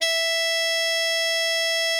bari_sax_076.wav